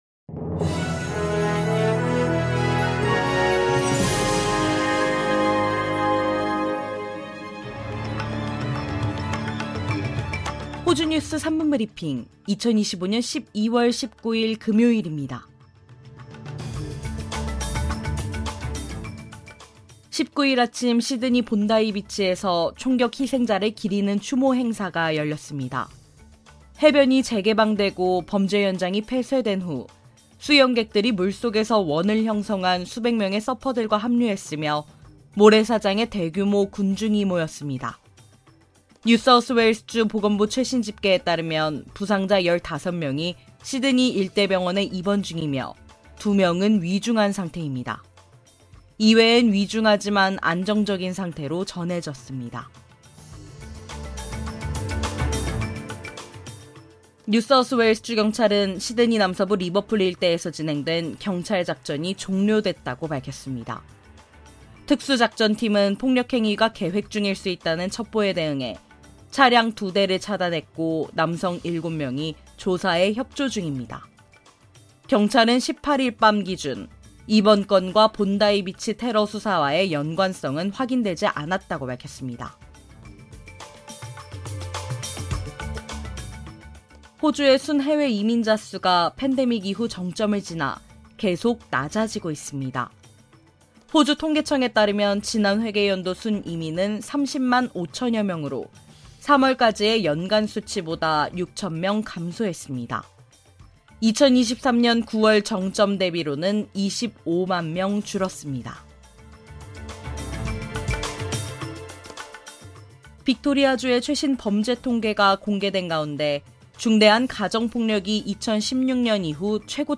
호주 뉴스 3분 브리핑: 2025년 12월 19일 금요일